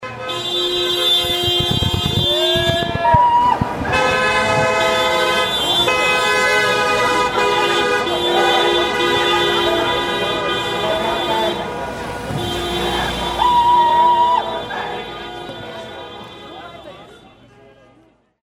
Und dann durfte wieder auf Teufel komm raus gehupt werden: